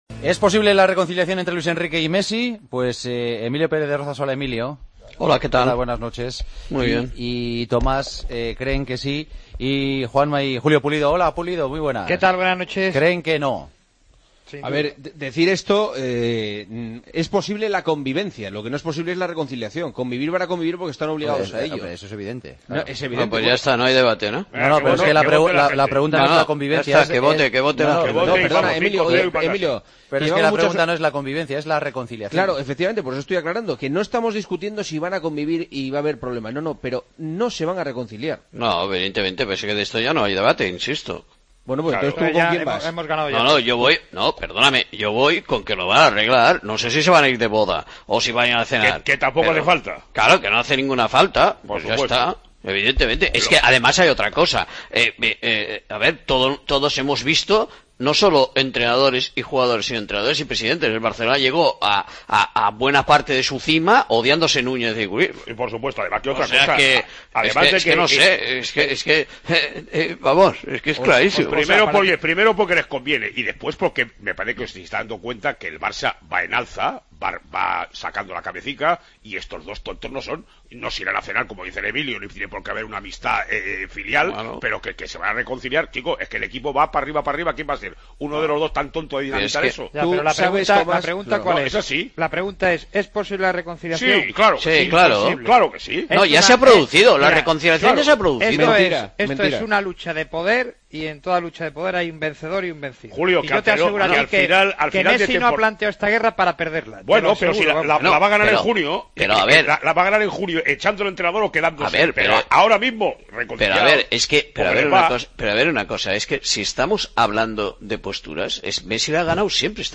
El debate de los jueves: ¿Es posible la reconciliación entre Luis Enrique y Messi?